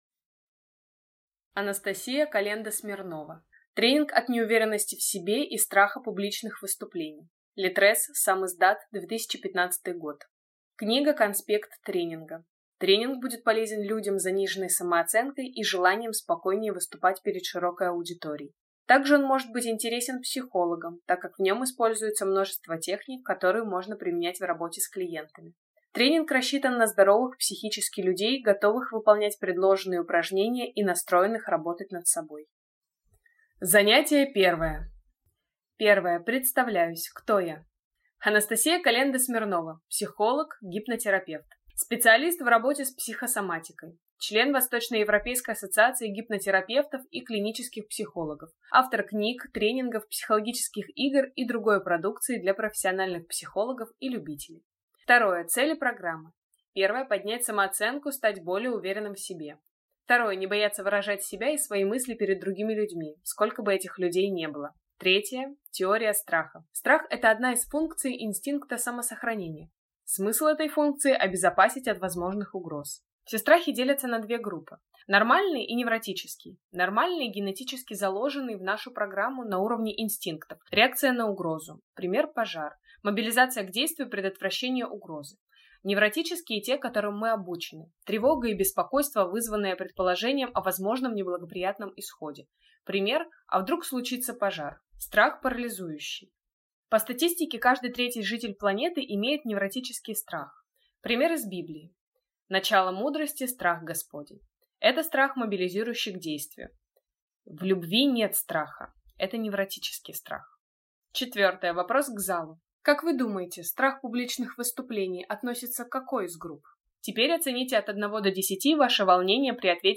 Аудиокнига Тренинг от неуверенности в себе и страха публичных выступлений | Библиотека аудиокниг